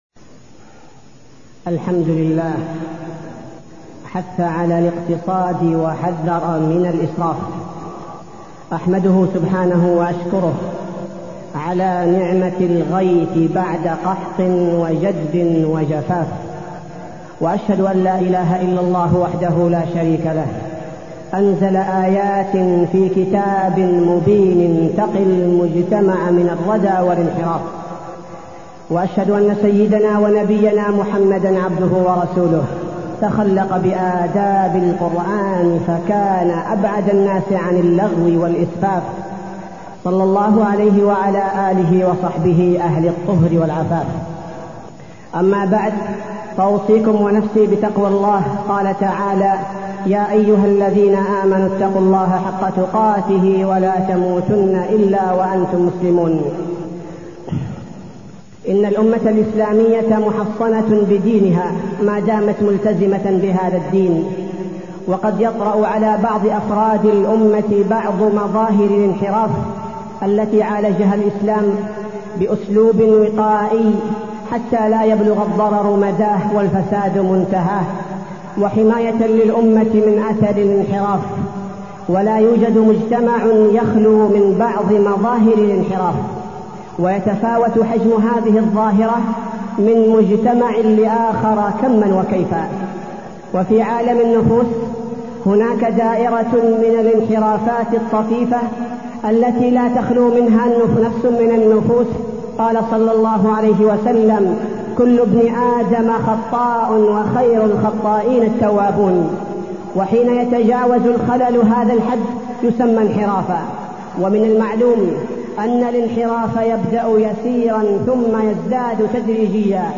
تاريخ النشر ١٤ شعبان ١٤٢١ هـ المكان: المسجد النبوي الشيخ: فضيلة الشيخ عبدالباري الثبيتي فضيلة الشيخ عبدالباري الثبيتي أسباب الانحراف وطرق الوقاية